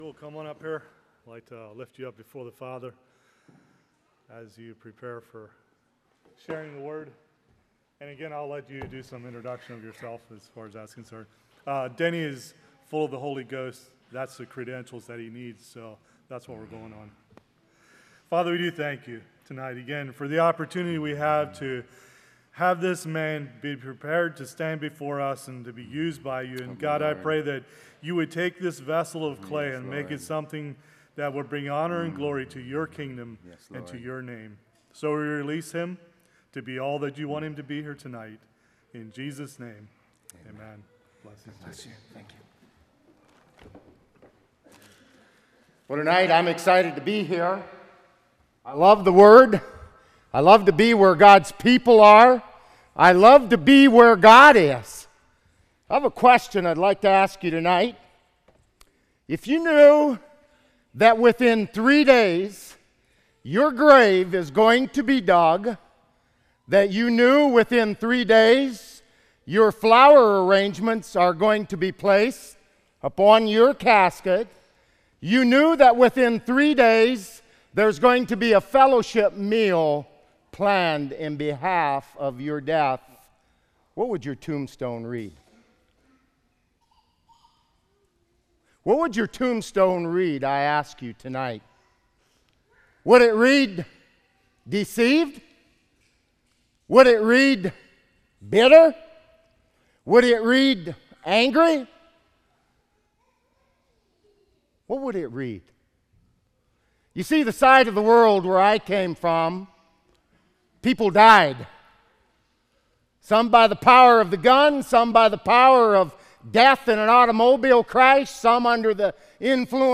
Revival Meetings Friday May 20, 2016